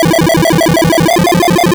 retro_synth_beeps_fast_01.wav